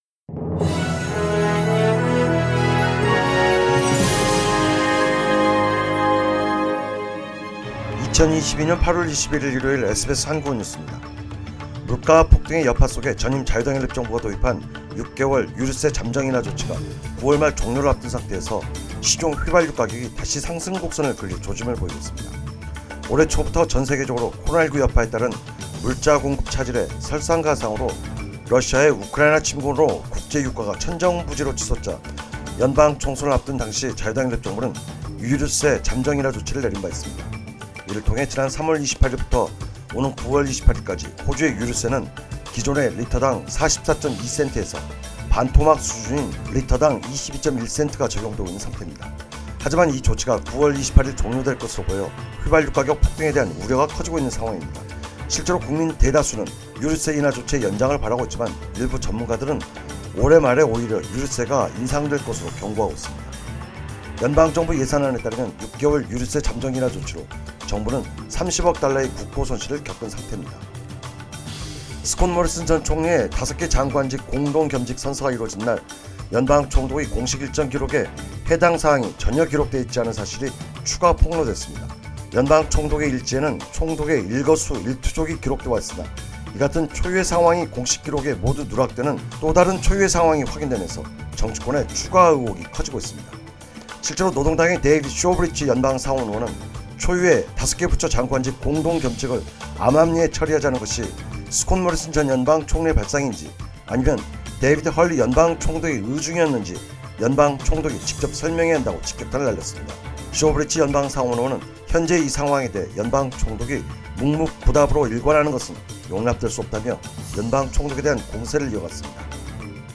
2022년 8월 21일 일요일 SBS 한국어 뉴스입니다.